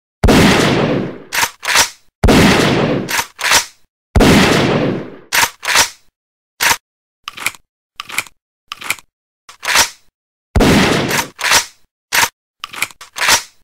دانلود آهنگ شاتگان از افکت صوتی اشیاء
دانلود صدای شاتگان از ساعد نیوز با لینک مستقیم و کیفیت بالا
جلوه های صوتی